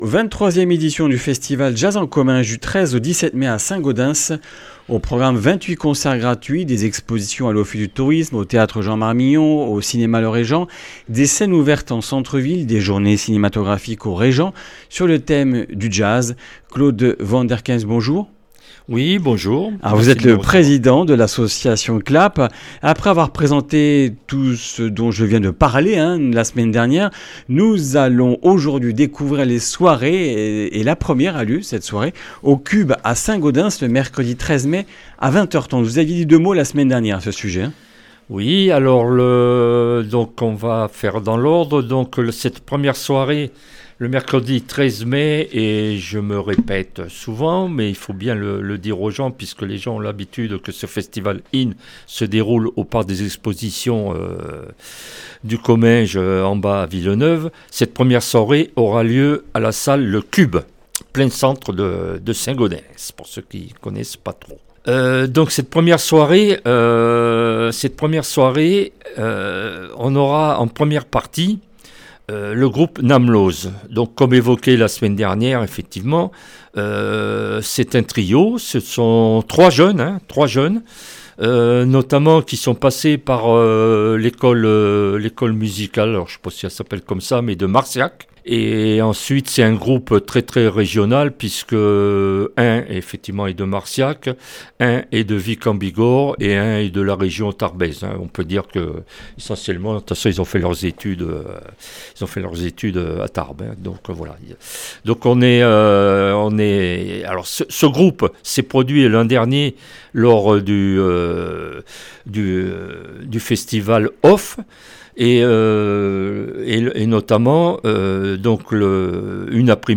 Comminges Interviews du 22 avr.